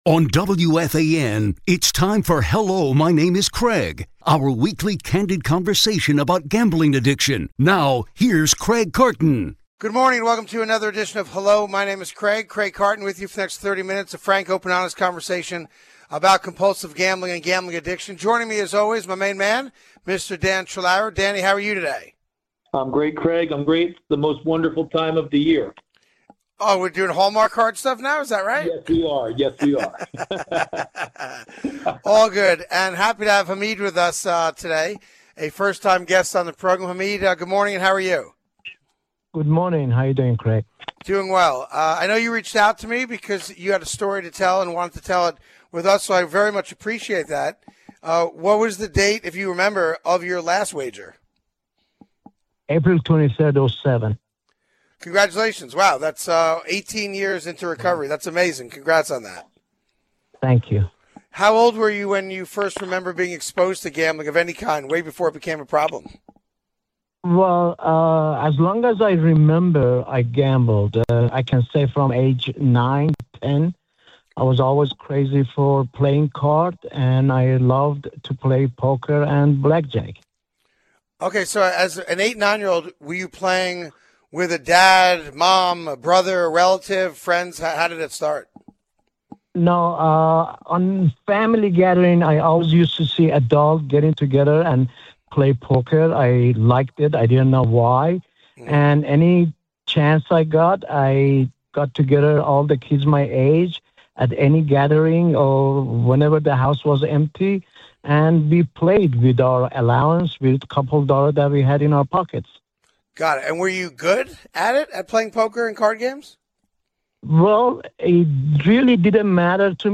A WEEKLY CANDID COVERSATION ON GAMBLING ADDICTION.